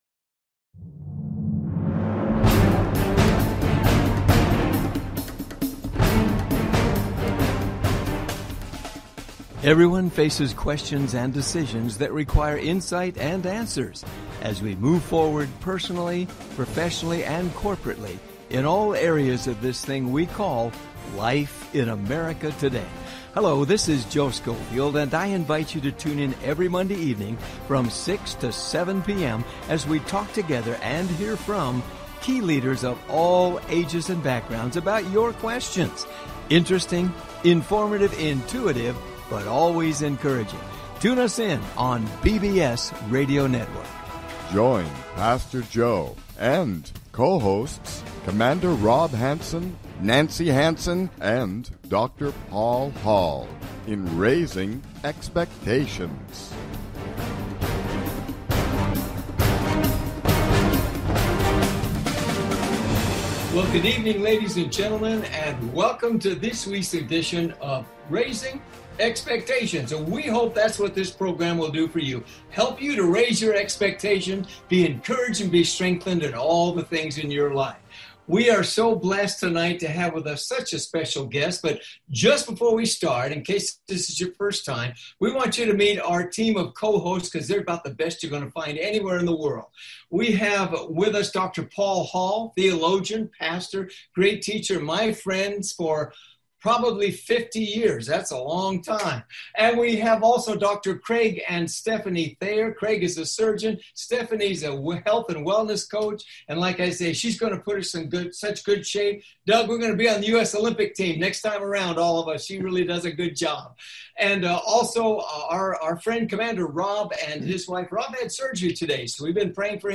Special guest: DOUG WEAD, conservative commentator, writer and served on White House staff with President H.W. Bush. He is a master historian and will share incredible insights into today’s political climate and our nations critical election.